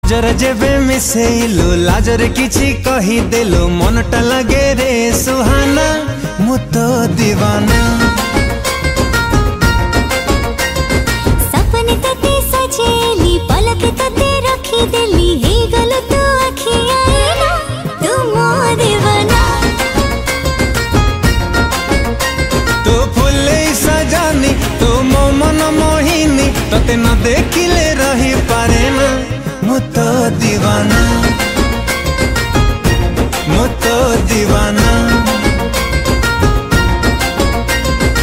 Odia Album Ringtones
Dance song